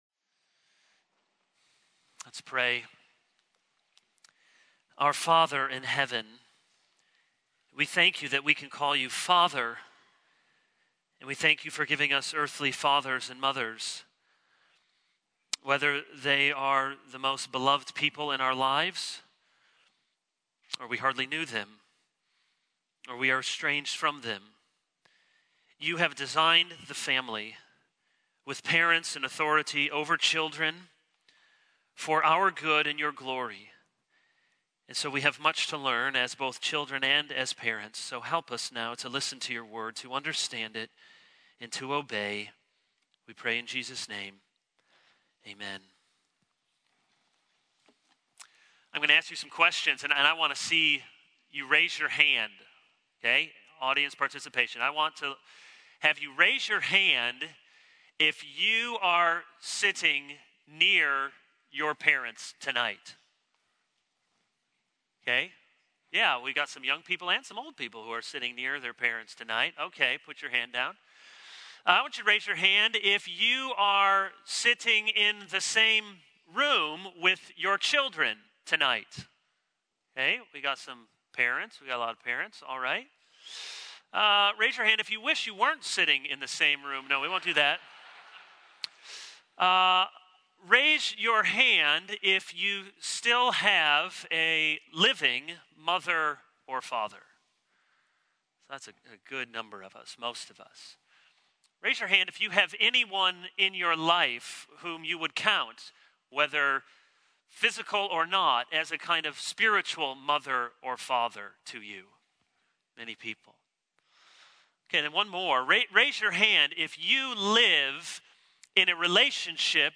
This is a sermon on Exodus 20:12.